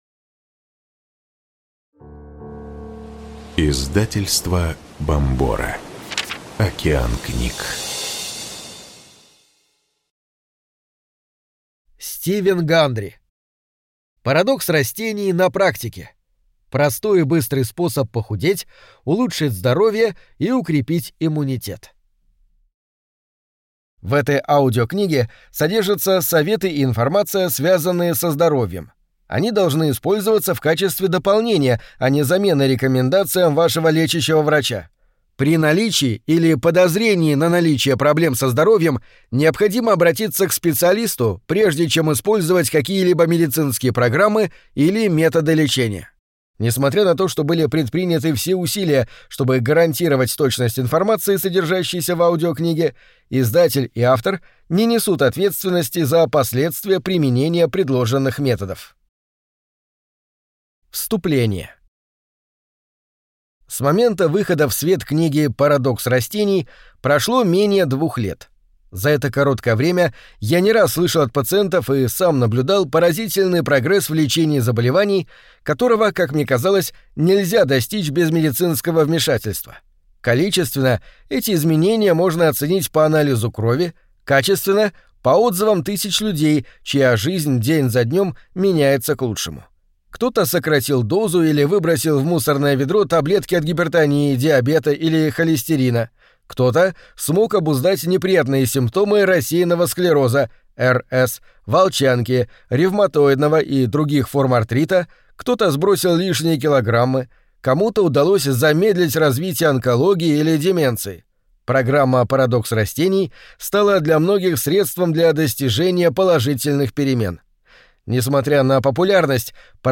Аудиокнига Парадокс растений на практике. Простой и быстрый способ похудеть, улучшить здоровье и укрепить иммунитет | Библиотека аудиокниг